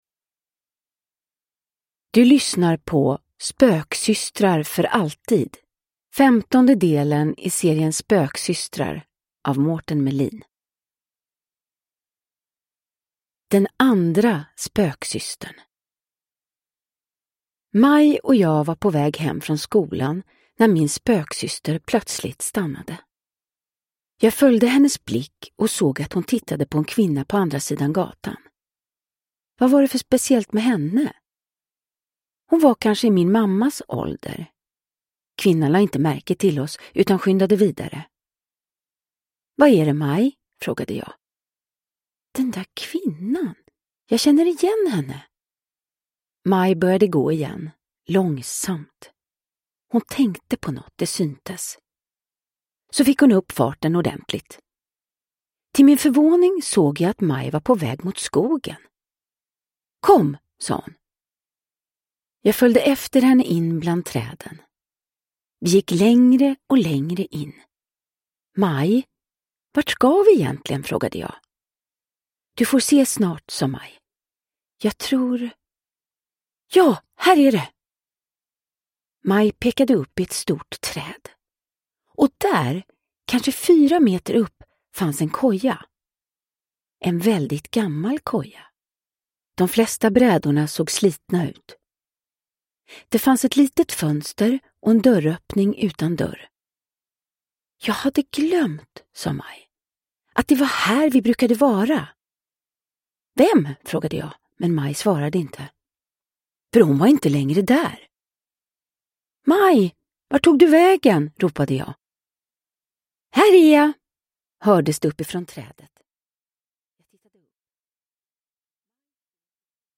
Spöksystrar för alltid – Ljudbok
Uppläsare: Vanna Rosenberg